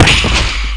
FMediumImpact1.mp3